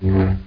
saber.mp3